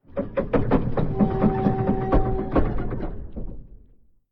Ambient4.ogg